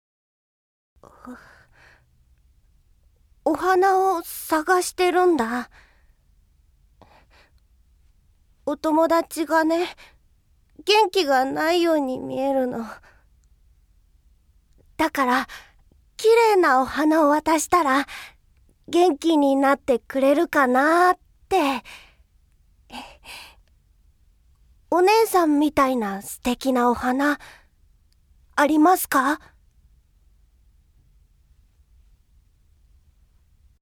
◆不運な女性◆
◆やさしい男の子◆